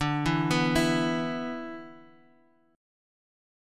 E5/D chord